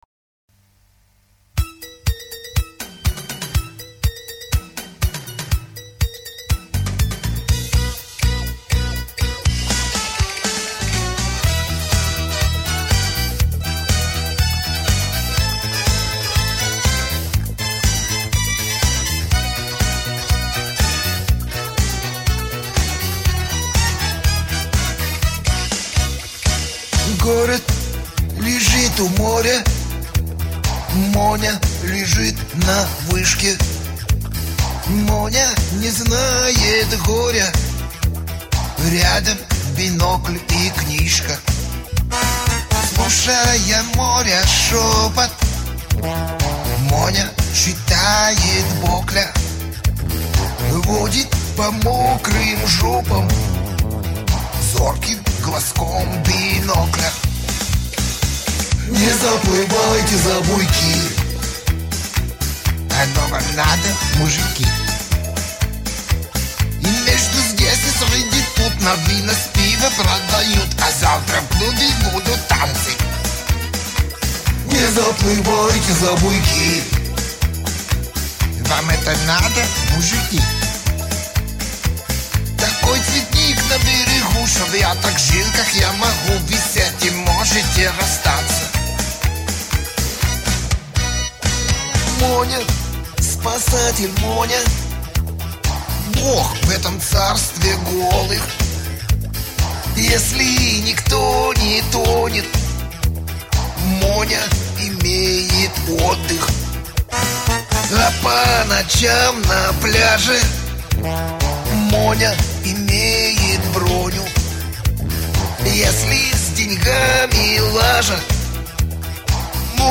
Совсем  забыли  мы старый шансончик-было время...да ушло.